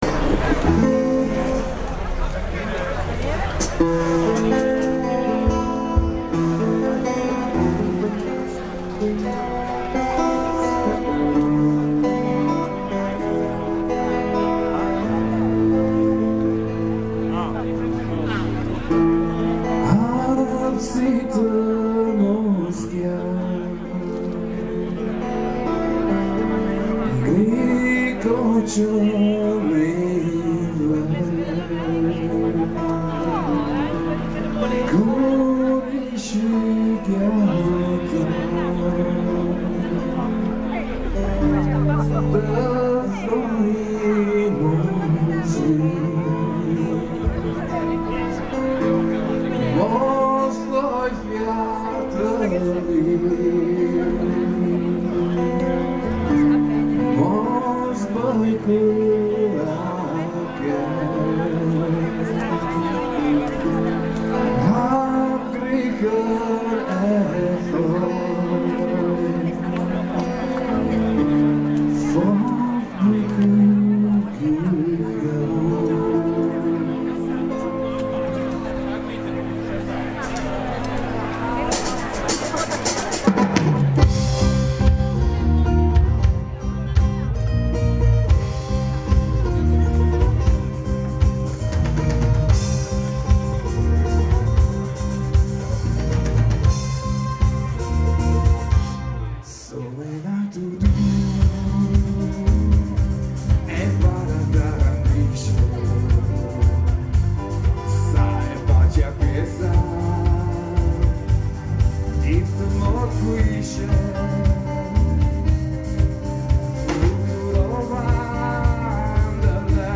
Festival Della Canzone Dialettale Molisana 2018: Serate Premorienza
Le pochissime canzoni, da me registrate senza alcuna vera voglia di farlo, sono state posizionate alla rinfusa.